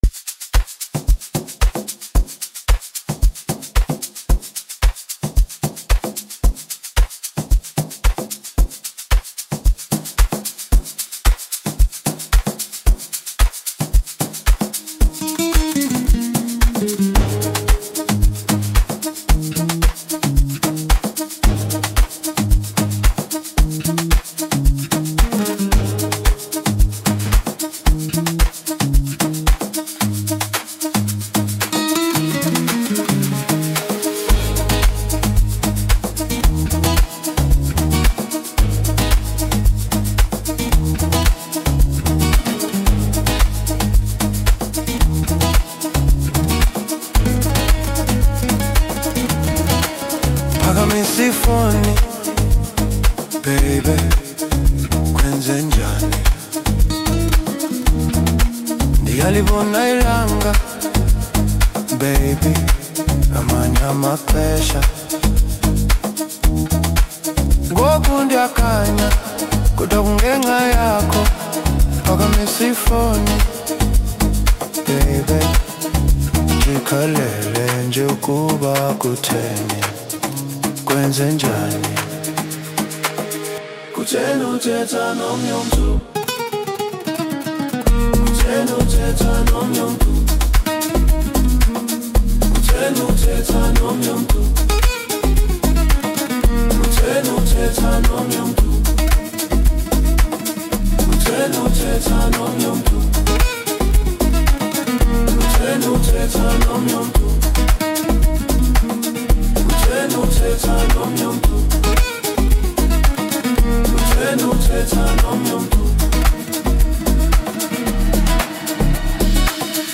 Home » Amapiano
South African singer